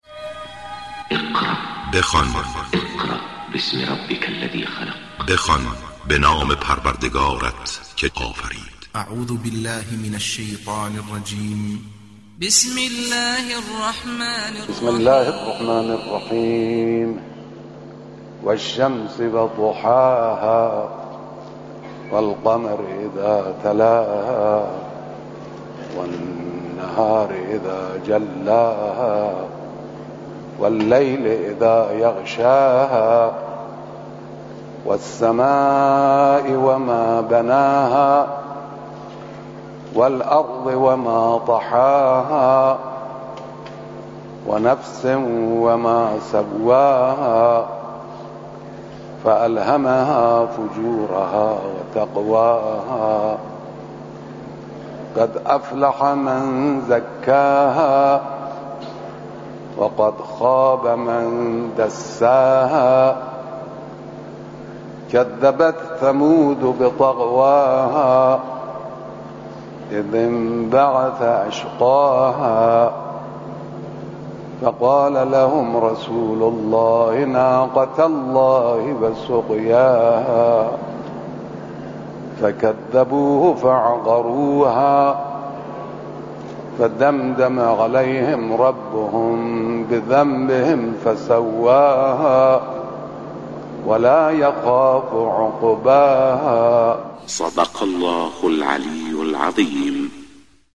صوت/ تلاوت سوره شمس توسط رهبر انقلاب
تلاوتی را از رهبر معظم انقلاب به مناسبت ماه مبارک رمضان می شنوید.